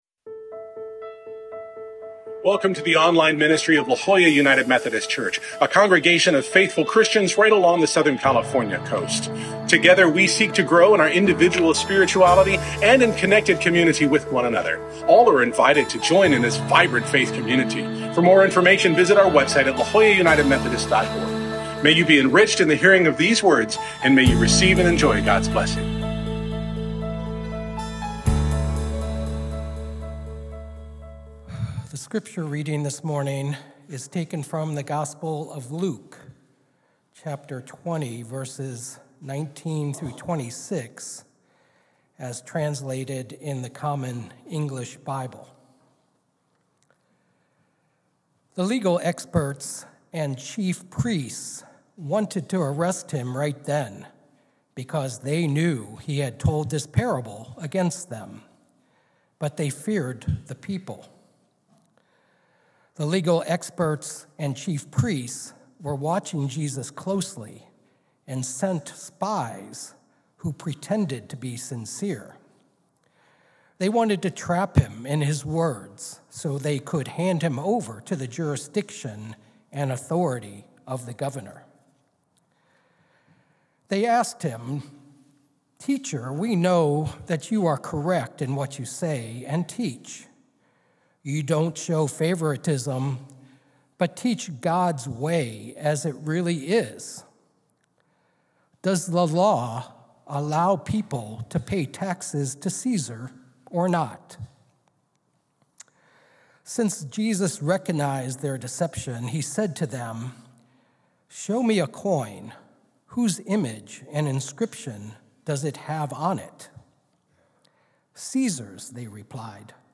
Identity Theft - La Jolla UMC